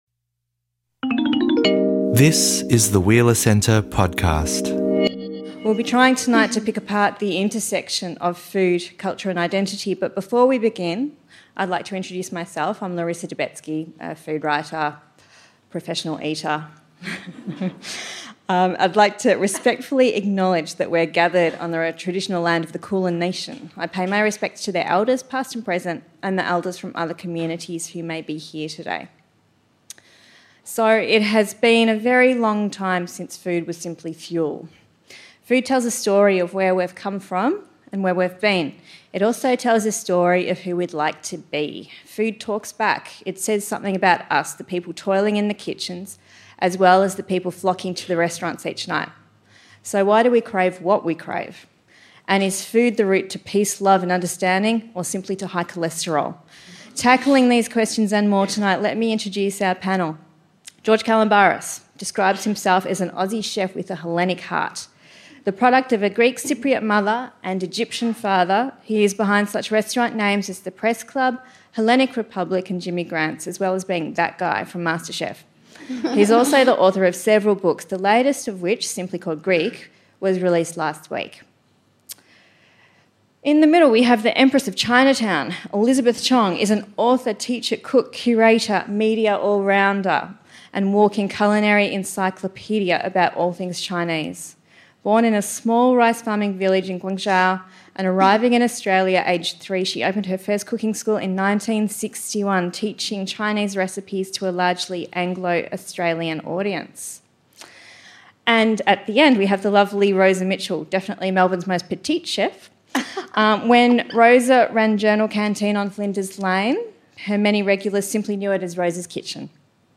in conversation with George Calombaris